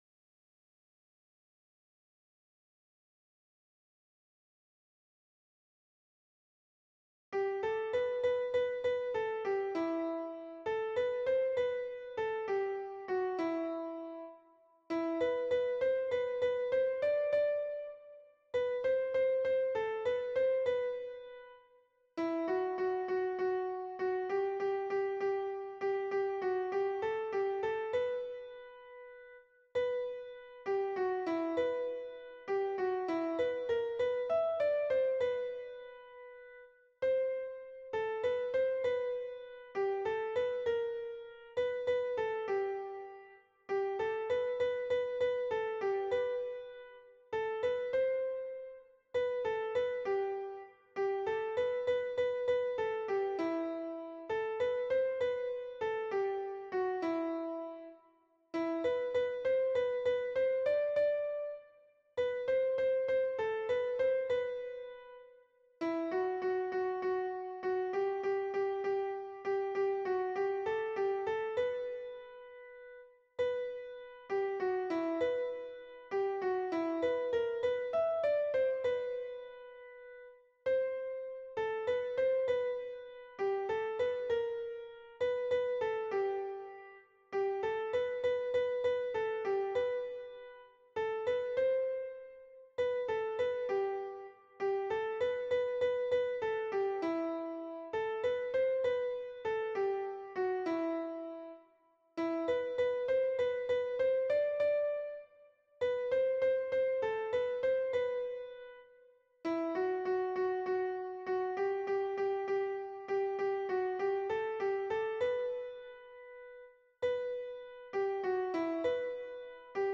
MP3 version piano
Soprano